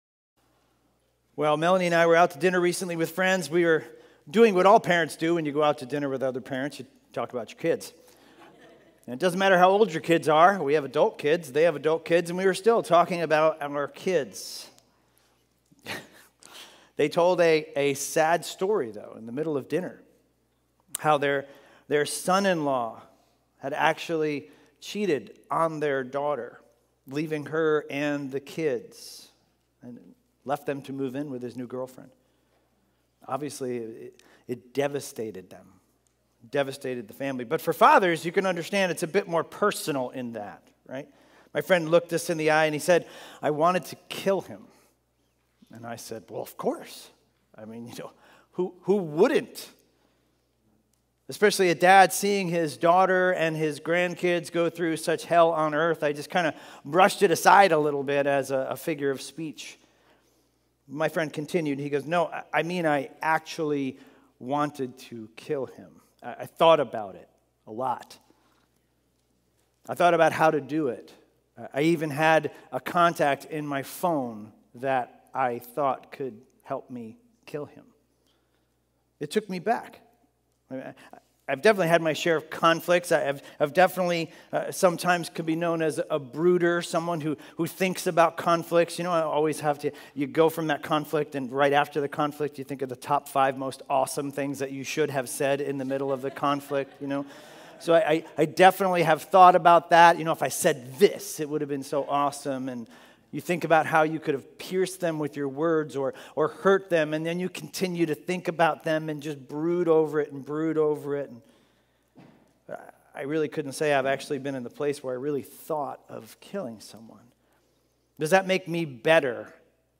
A message from the series "Romans." In Romans 4:1-12 we learn that faith is what counts for justification.